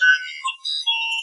computer2.ogg